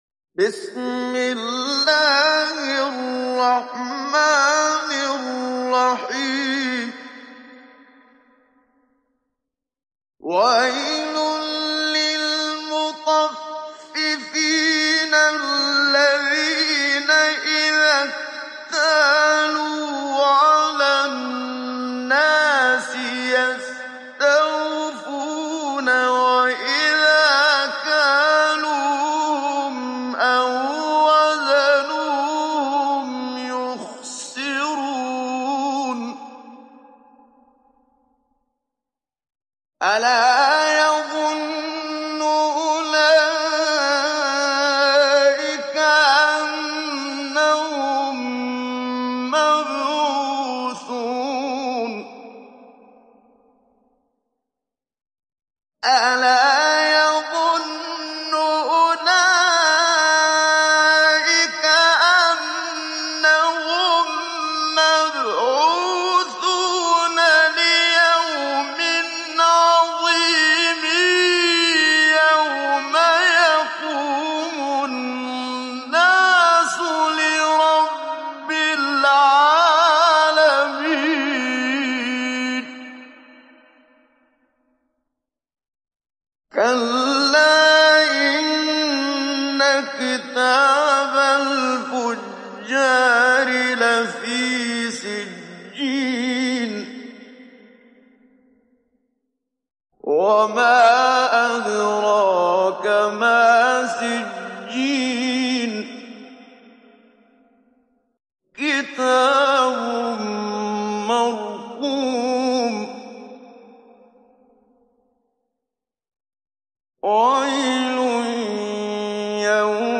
Download Surat Al Mutaffifin Muhammad Siddiq Minshawi Mujawwad